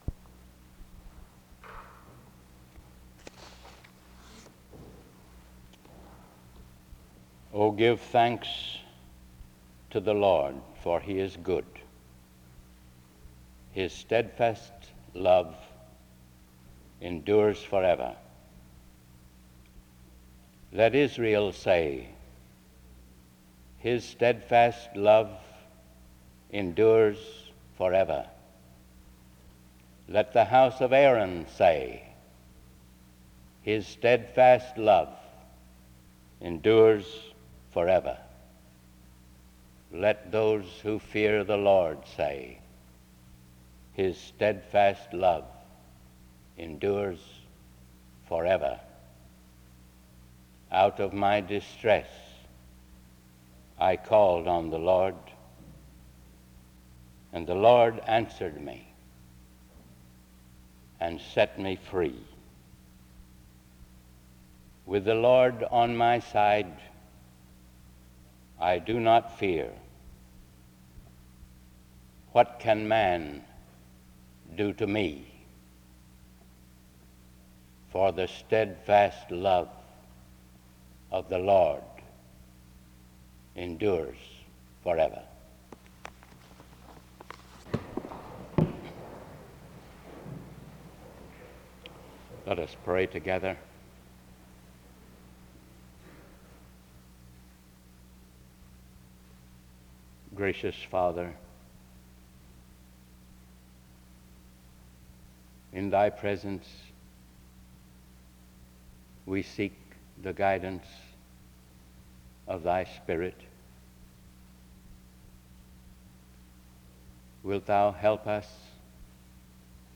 The service concluded with a song.
SEBTS Chapel and Special Event Recordings